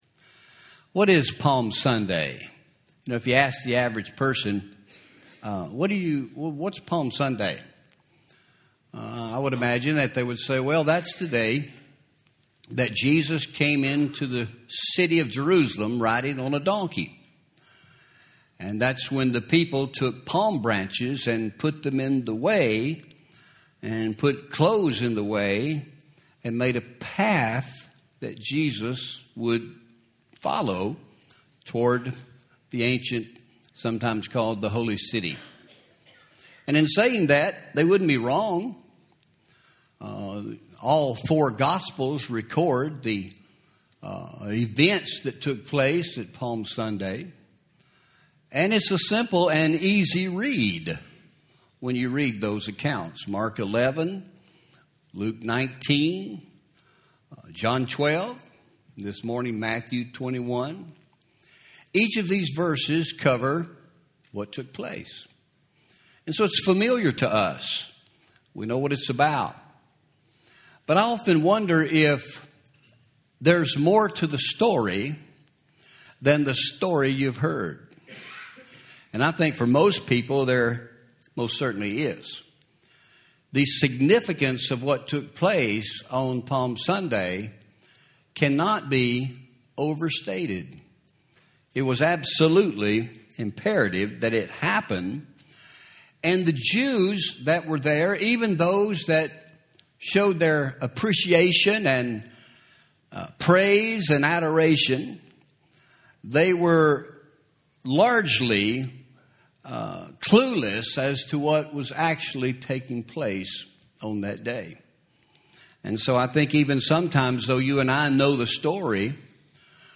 Home › Sermons › Palm Sunday’s Underlying Lesson